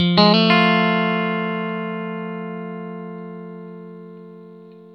RIFF1-120F.A.wav